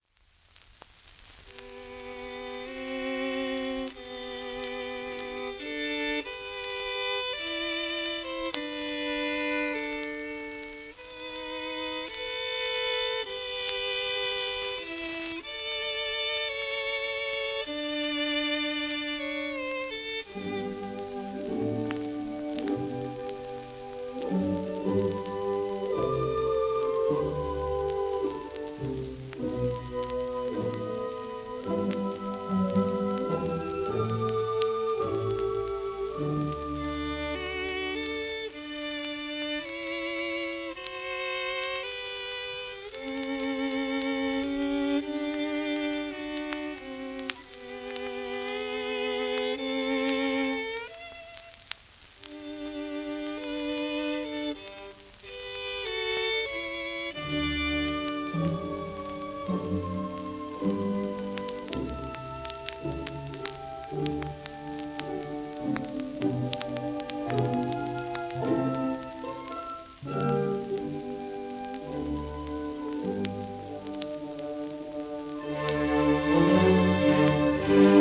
盤質A-/B+ *サーフェイスノイズ,キズ
1940年代の録音盤。